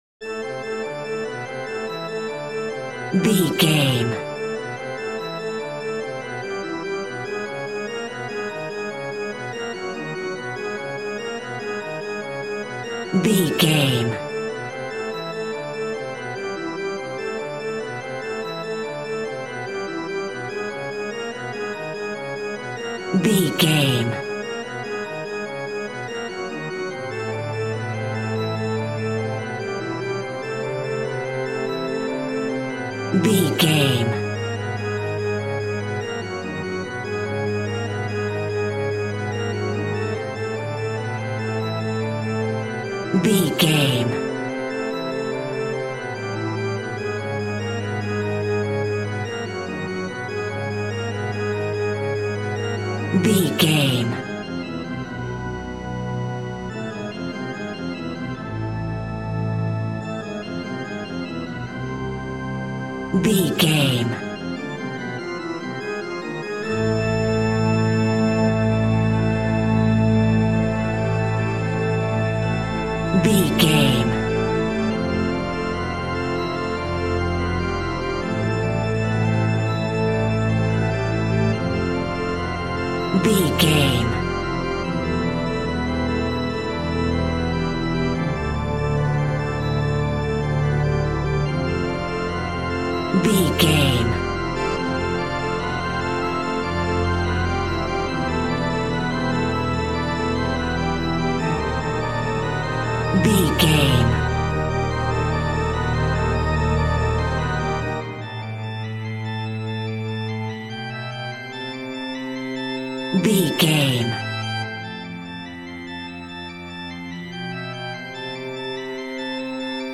In-crescendo
Aeolian/Minor
ominous
dark
haunting
eerie
synth
ambience
pads